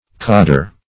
codder - definition of codder - synonyms, pronunciation, spelling from Free Dictionary Search Result for " codder" : The Collaborative International Dictionary of English v.0.48: Codder \Cod"der\, n. A gatherer of cods or peas.